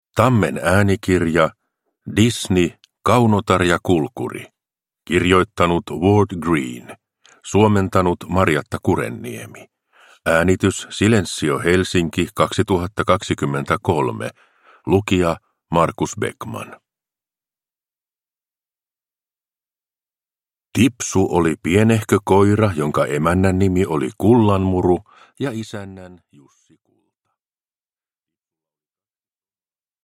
Kaunotar ja Kulkuri – Ljudbok – Laddas ner